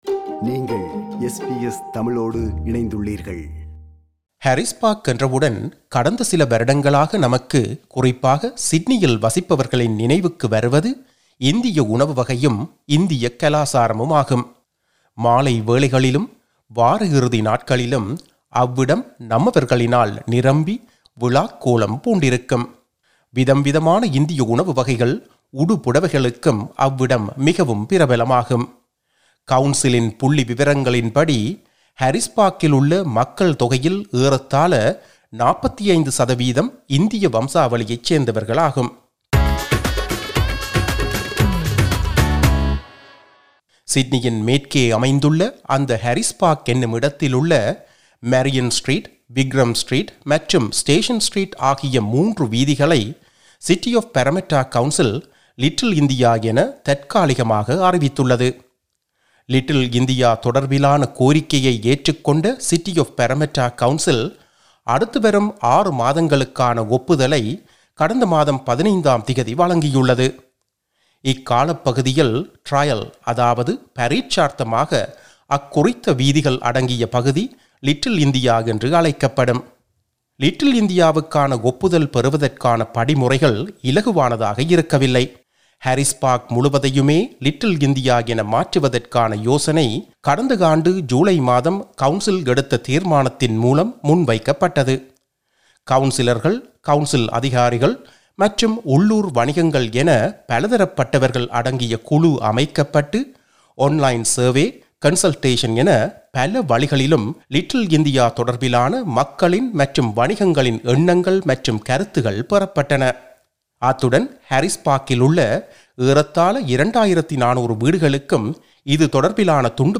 presents a feature on 'Little India' speaking to Councillor Sameer Pandey